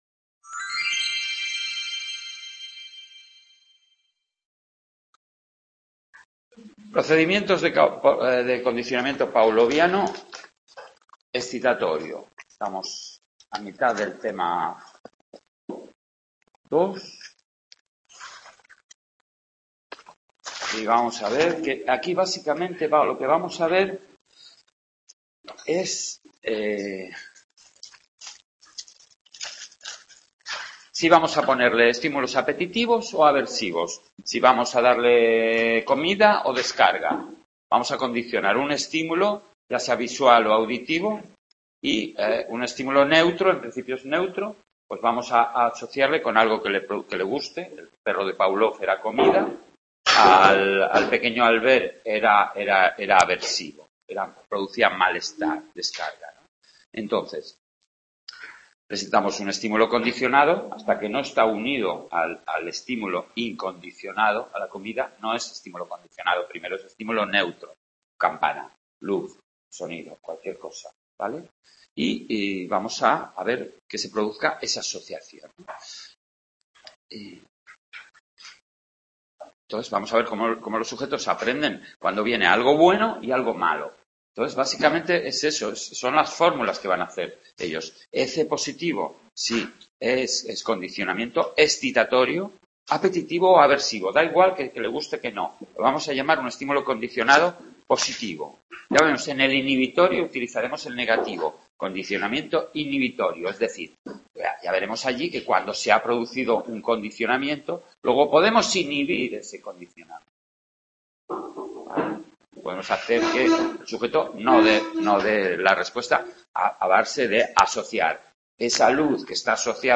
Grabación INTECCA de la segunda parte del tema 2 de psicología del Aprendizaje, realizada en el Aula de Sant Boi